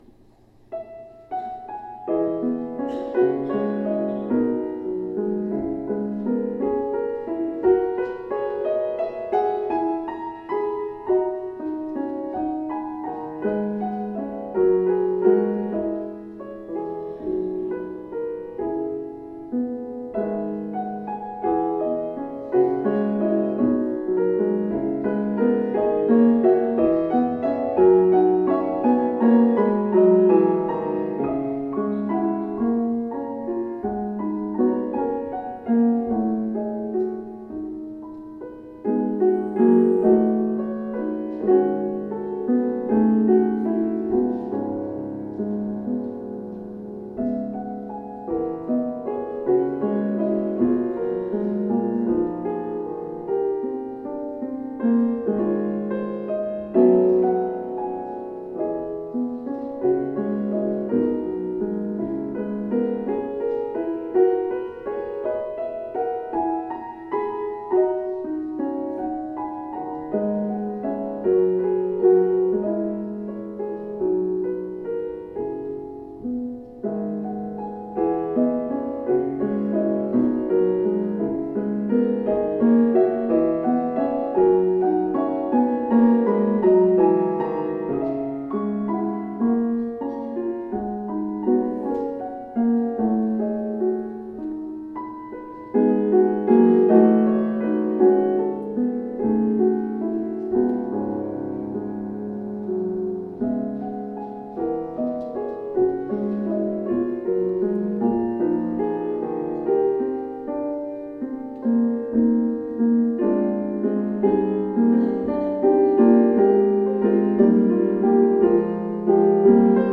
OFFERTORY -  Barcarolle No.1  - Ned Rorem